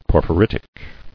[por·phy·rit·ic]